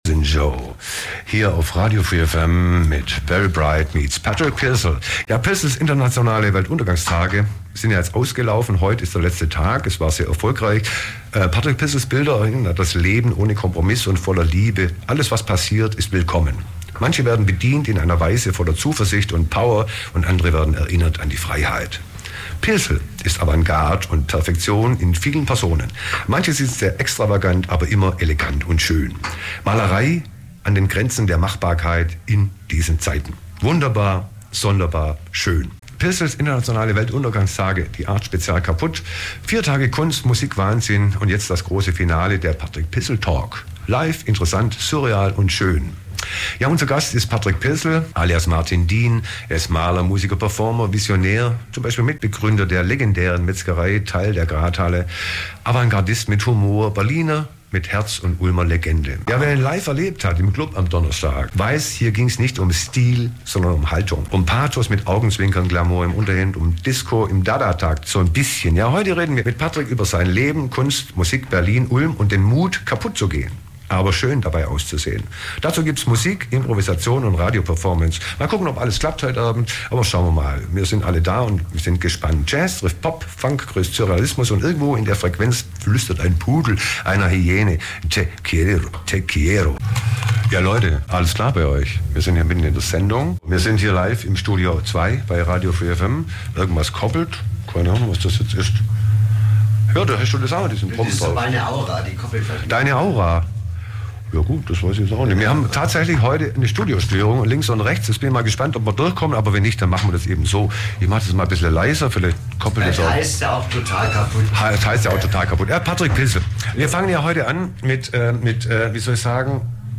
im Interview Teil 1: Über seine Kindheit und Zeit in Ulm